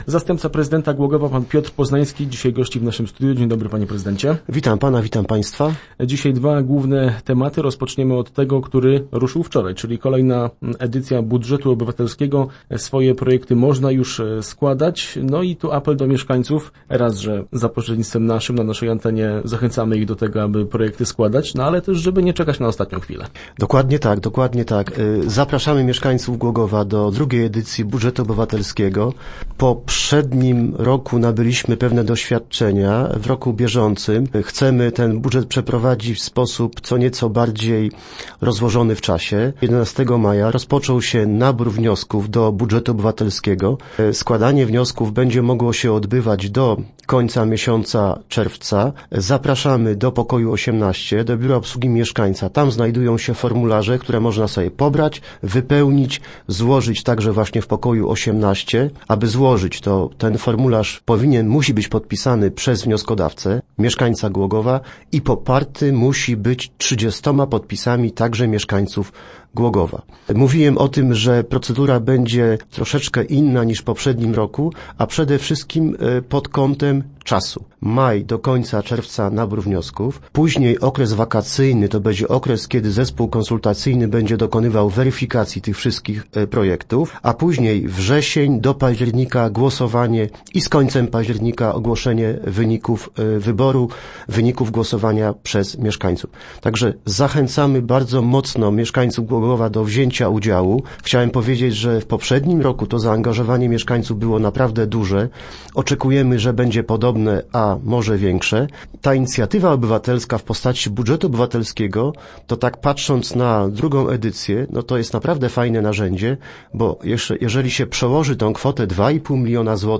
0513_re_poznanski.jpgW środę w radiowym studiu z zastępcą prezydenta miasta Piotrem Poznańskim rozmawialiśmy o naborze projektów do budżetu obywatelskiego, a także o utrudnieniach jakie wystąpią podczas remontu wiaduktu na ul. Rudnowskiej.